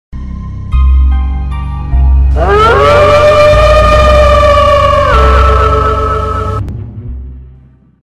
Главная » 2012 » Май » 25 » Оповещение СМС. Звериный вой
Хотите скачать без SMS короткий mp3 фрагмент "Звериный вой"?